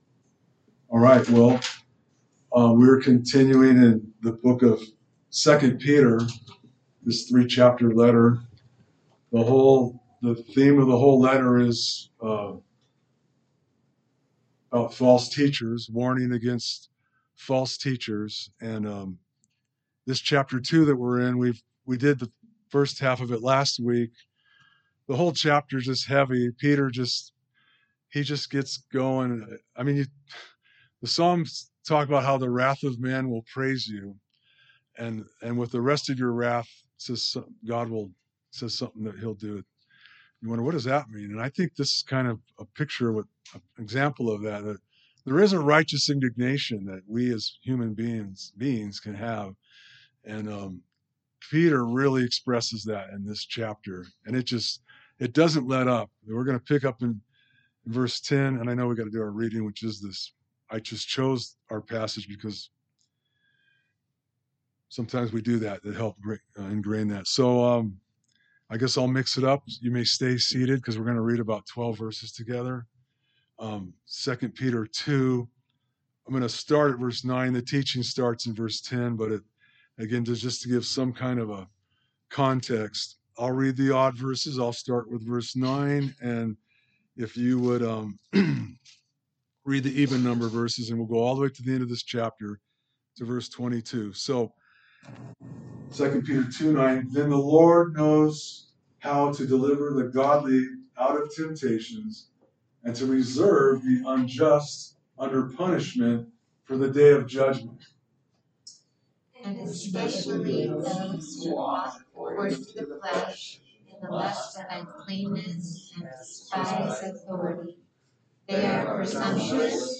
A message from the series "2 Peter."